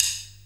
D2 SDRIM06-R.wav